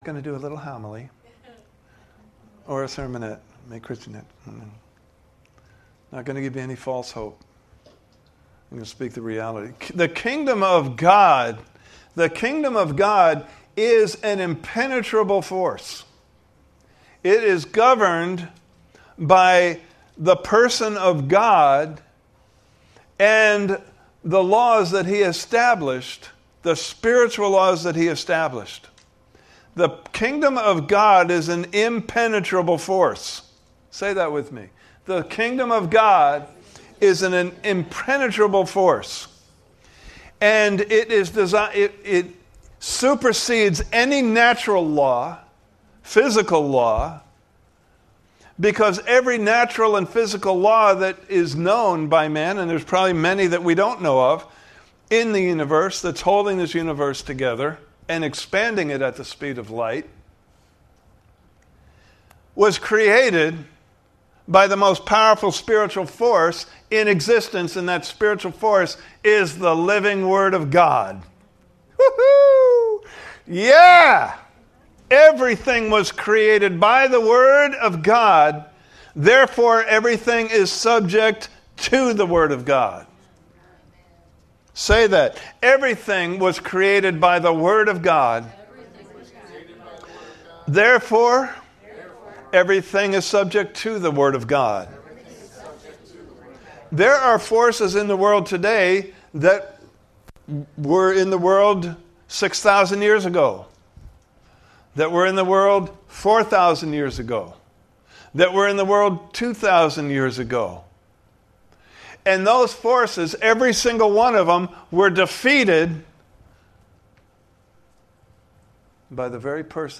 Unshakeable Trust Service Type: Sunday Morning Service « Part 1